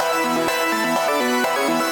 SaS_Arp05_125-E.wav